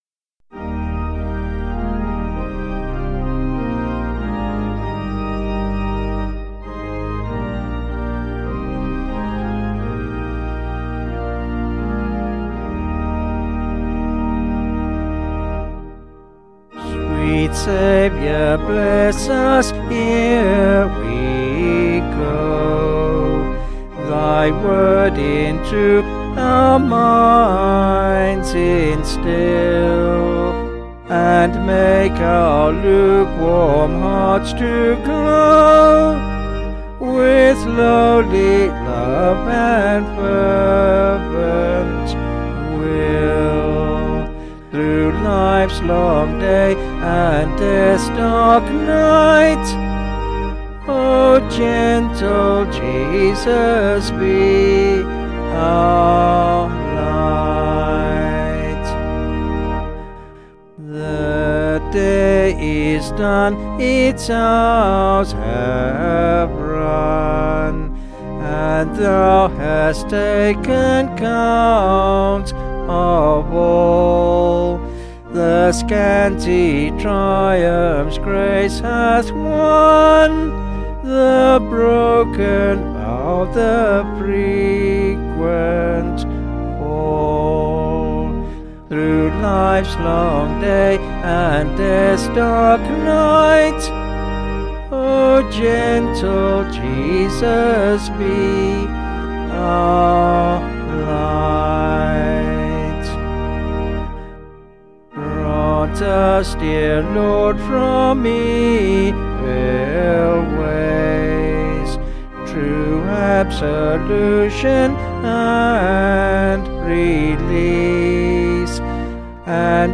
Vocals and Organ   706.6kb Sung Lyrics